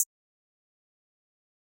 Closed Hats
Nerd HH.wav